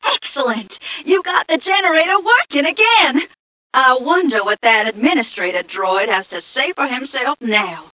1 channel
mission_voice_m2ca010.mp3